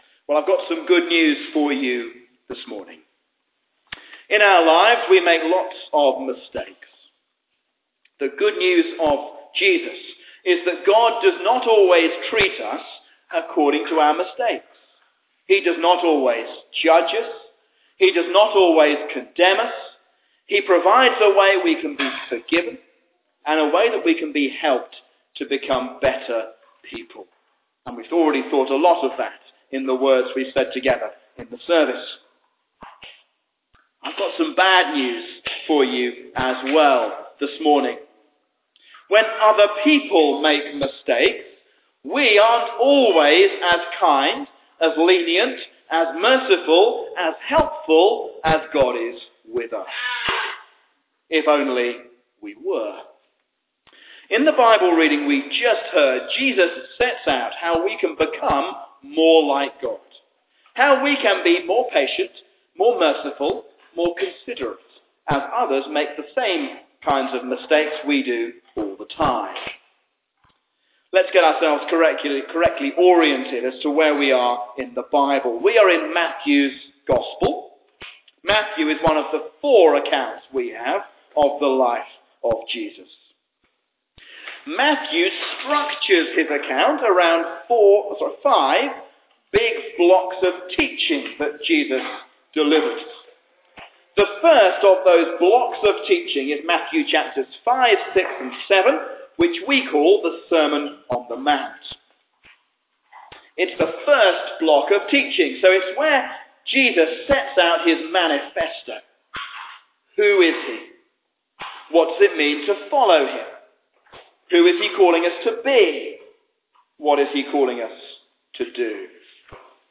A sermon on Matthew 7:1-6